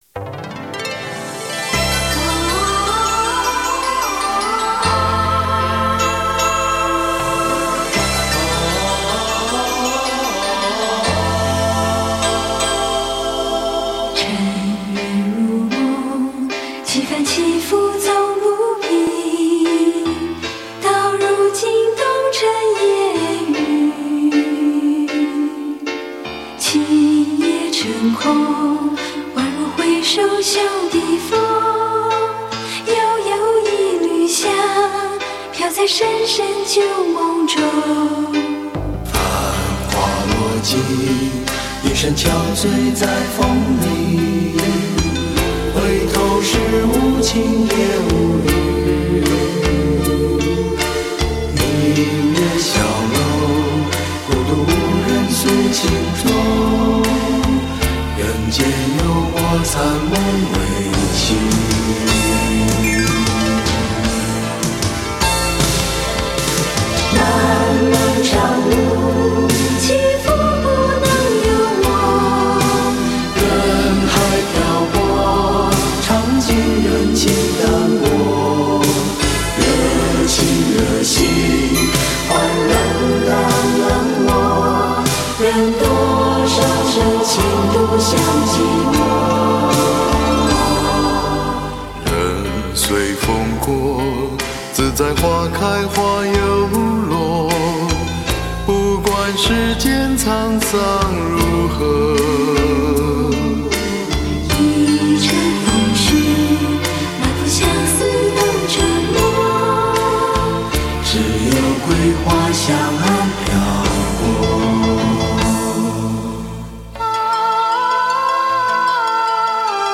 男女混音合唱
精选了国语老歌34首重新演绎，不一样的味道，一样的怀旧感受。